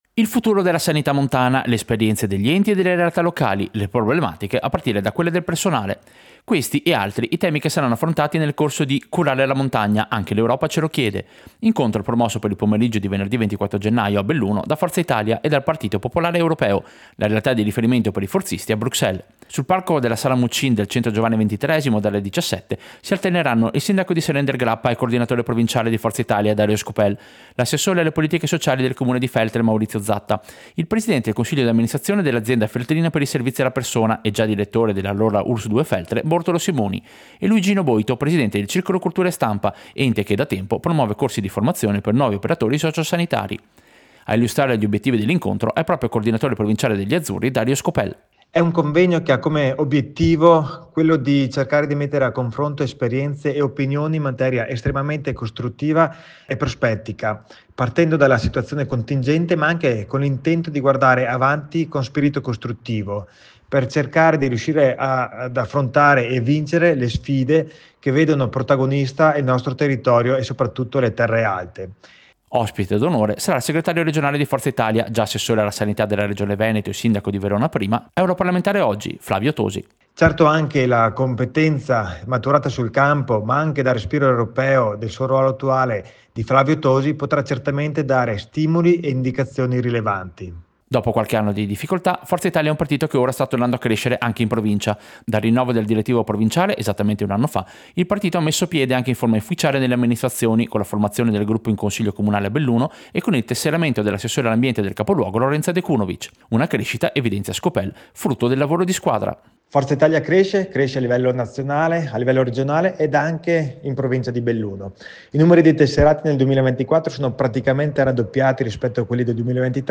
Servizio-Curare-montagna-Forza-Italia.mp3